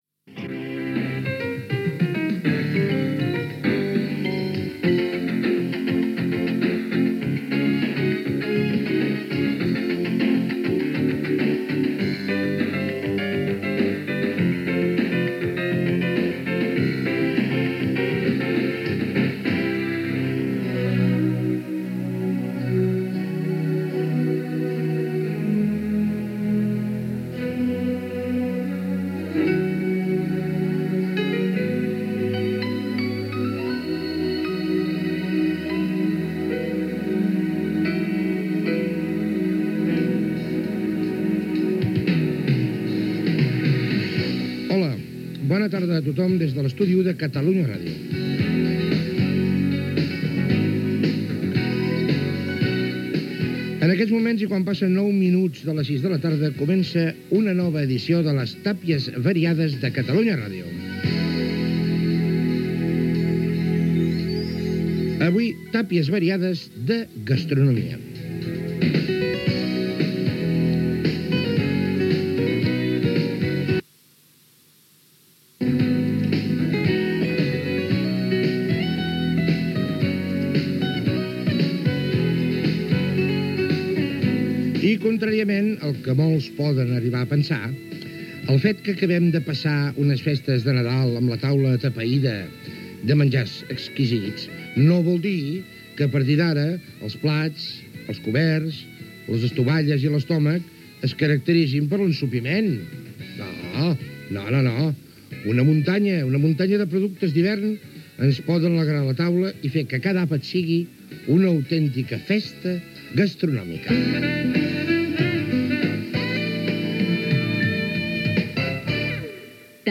Indicatiu de l'emissora, presentació, indicatiu del programa
Gènere radiofònic Divulgació